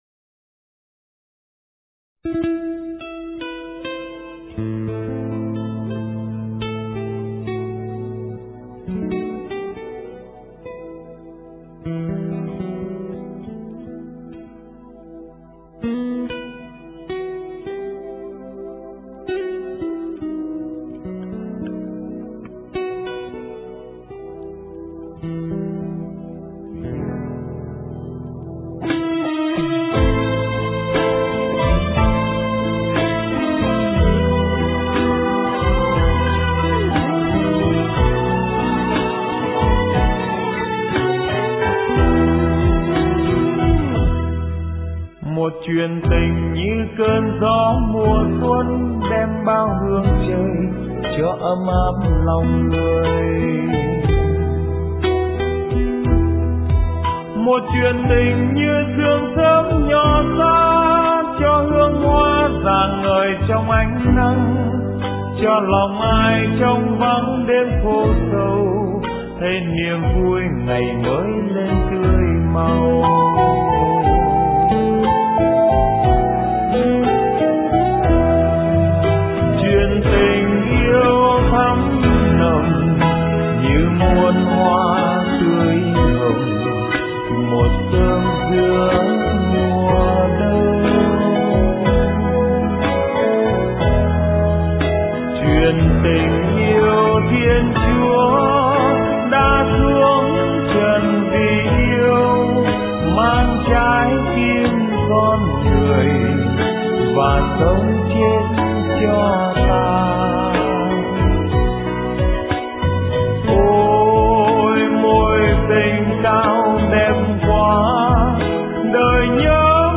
* Thể loại: Giáng Sinh